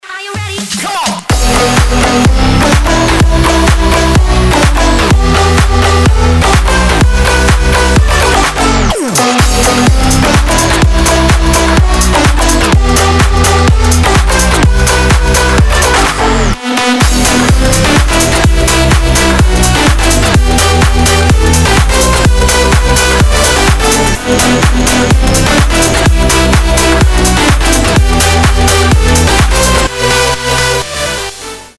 громкие
мощные
энергичные
progressive house
Стиль: future house